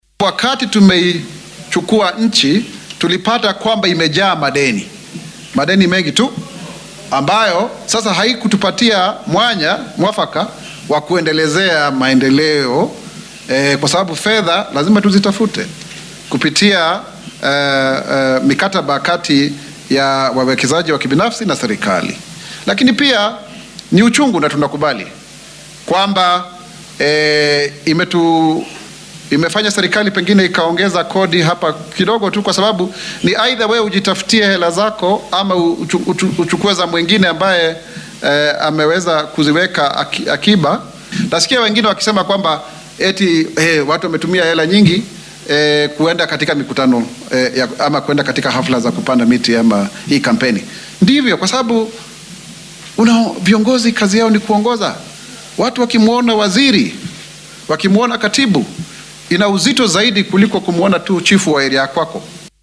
Issac Mwaura ayaa arrimahan ka hadlay xilli uu soo bandhigay warbixintiisi ugu horreysay tan iyo markii loo magacaabay xilka afhayeenka dowladda dhexe.